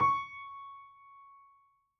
piano_073.wav